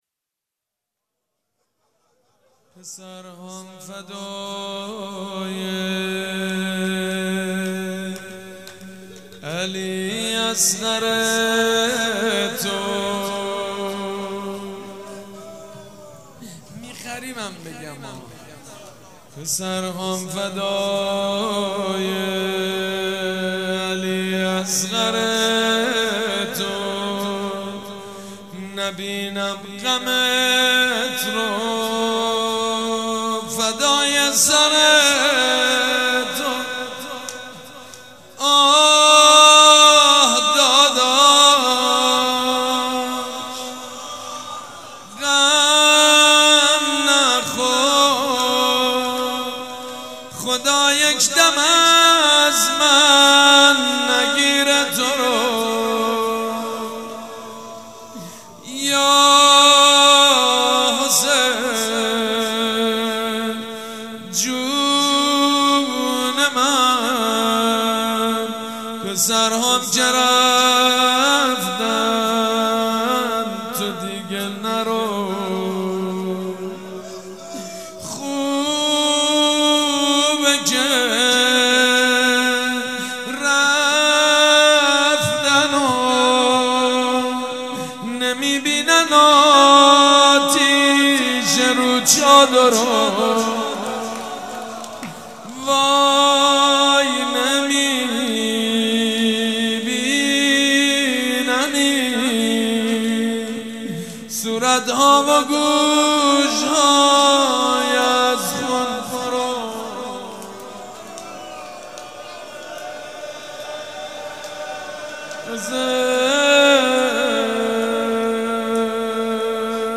روضه
مراسم عزاداری شب چهارم